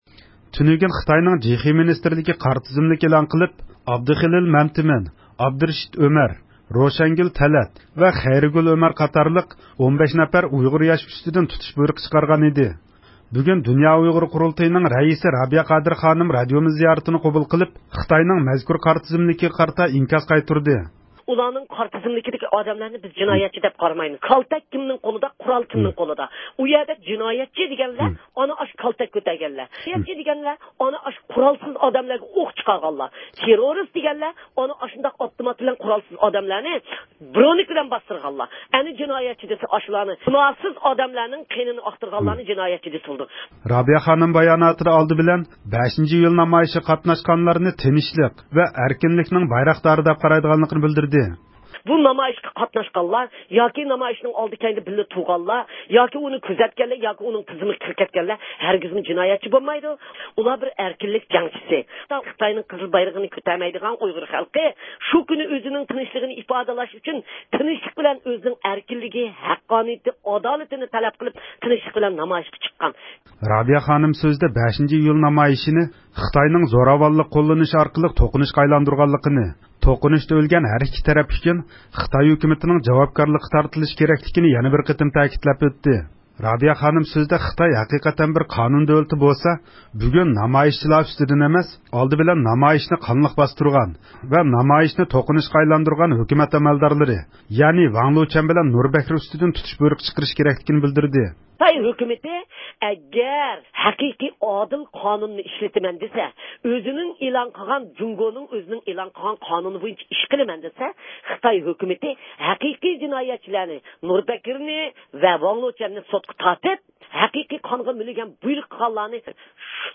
بۈگۈن دۇنيا ئۇيغۇر قۇرۇلتىيىنىڭ رەىسى رابىيە قادىر مۇخبىرىمىزنىڭ زىيارىتىنى قوبۇل قىلىپ، خىتاينىڭ مەزكۇر قارا تىزىملىكىگە ئىنكاس قايتۇردى.